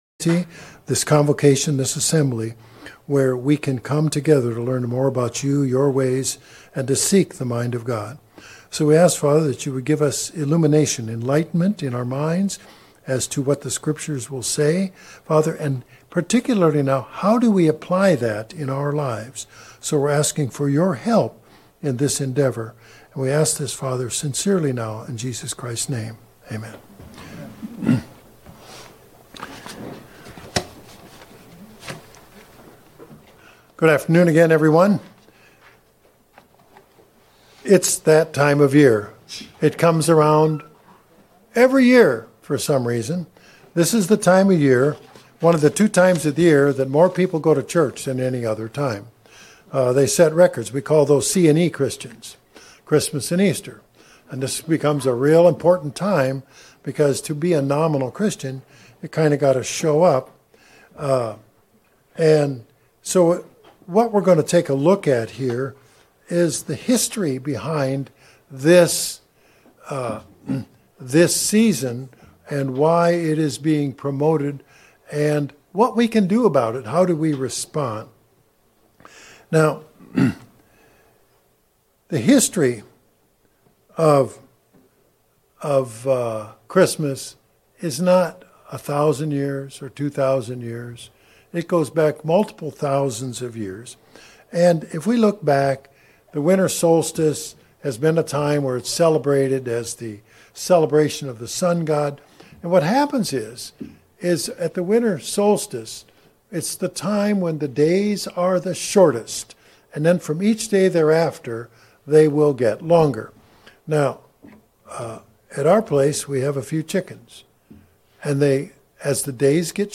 Bible Study Christmas